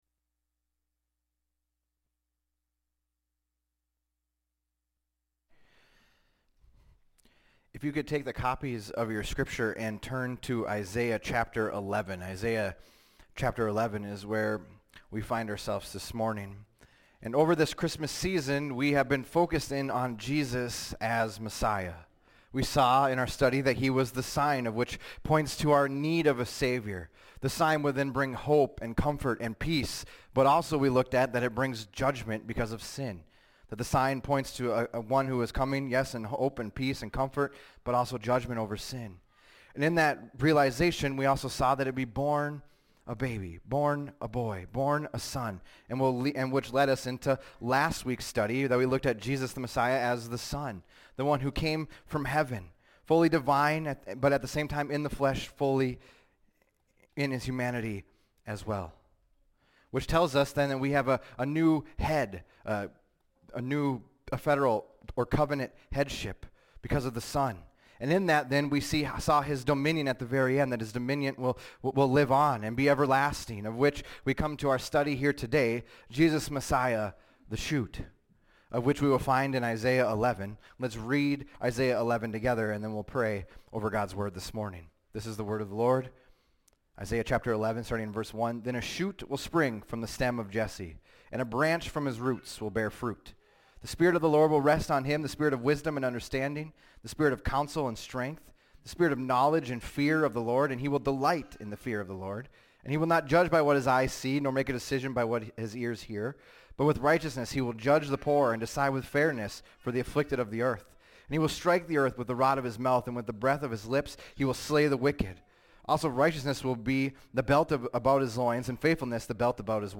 fbc_sermon_122125.mp3